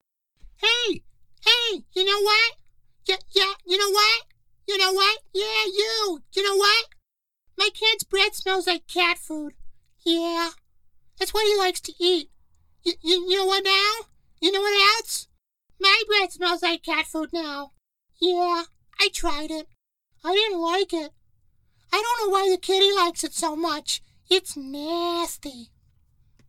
Character / Cartoon
0822Kid_Voice_Demo_Ya_Know_What_Mastered_Final.mp3